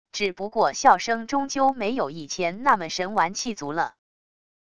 只不过笑声终究没有以前那么神完气足了wav音频生成系统WAV Audio Player